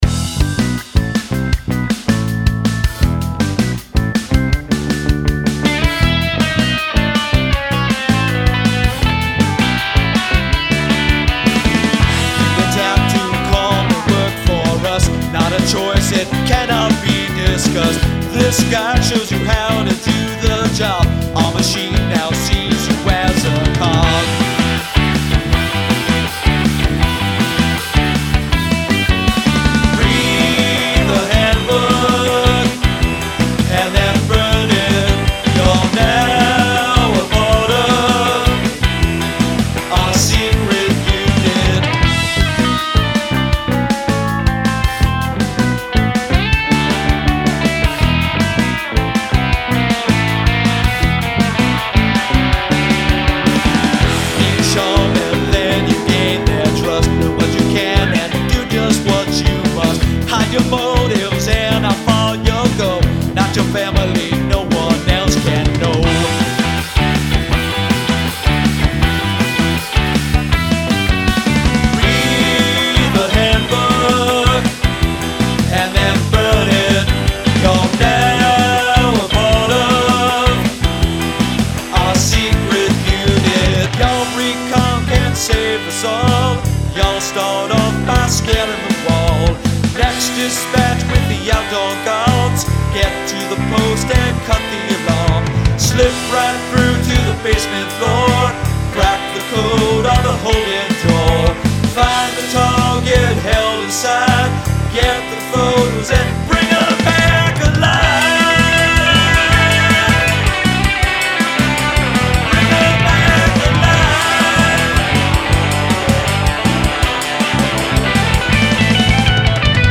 headbobber for sure, very 1980s indie rock